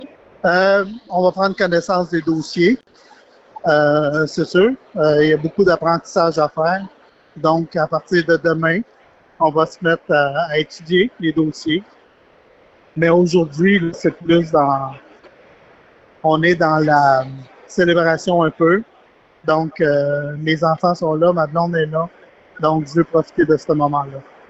En entrevue, il a mentionné qu’il était prêt à relever ce nouveau défi.